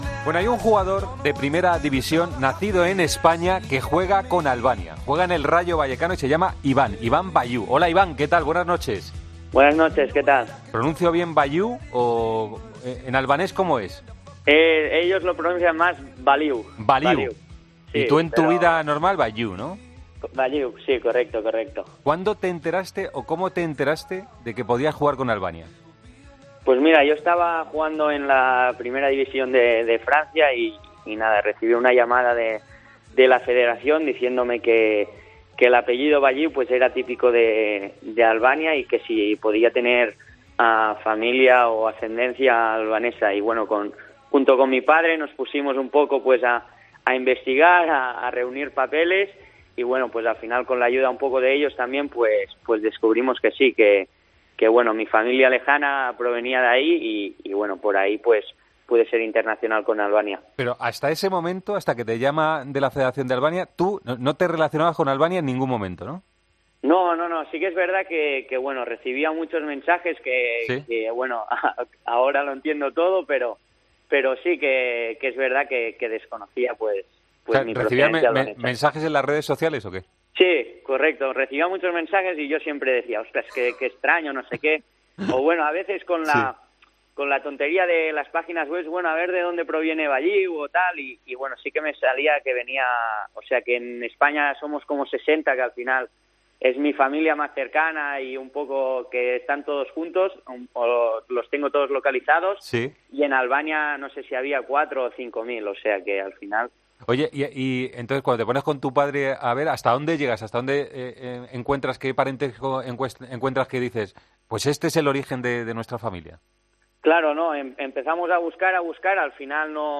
Iván Balliu explica en El Partidazo de COPE cómo descubrió su origen albanés